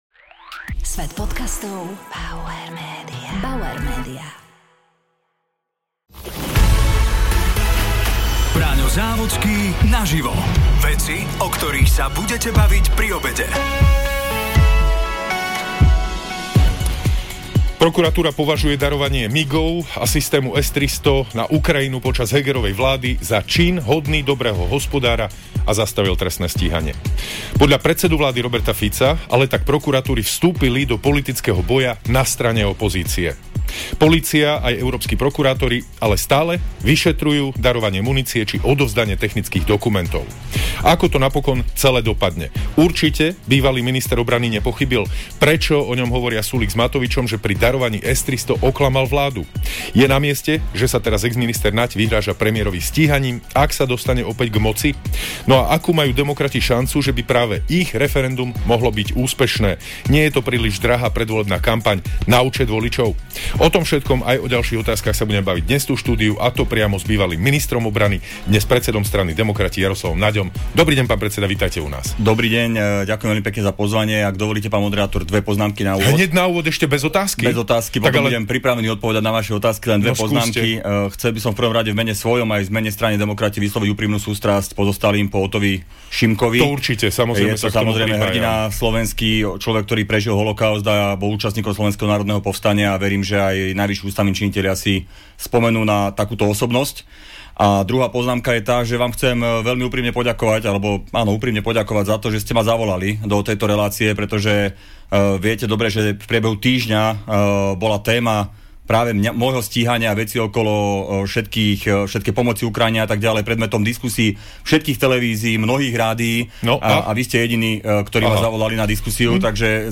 Braňo Závodský sa rozprával s exministrom obrany a predsedom strany Demokrati Jaroslavom Naďom.